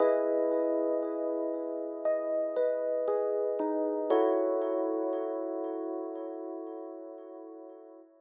04 ElPiano PT2.wav